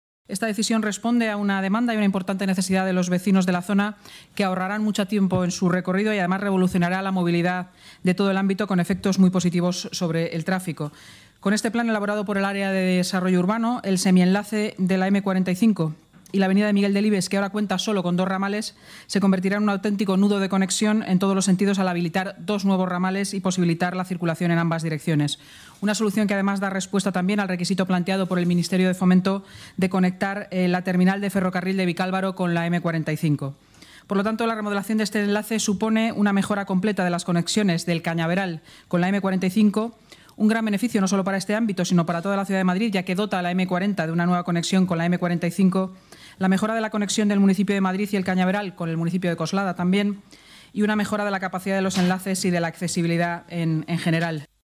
Nueva ventana:Inmaculada Sanz, portavoz municipal, habla sobre la remodelación del enlace a la M-45 en El Cañaveral